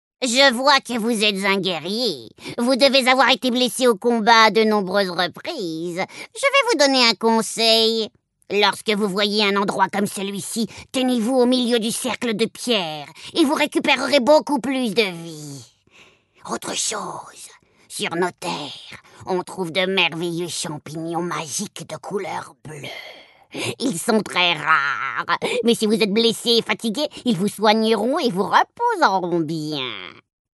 Sournoise, maligne, voix nasillarde https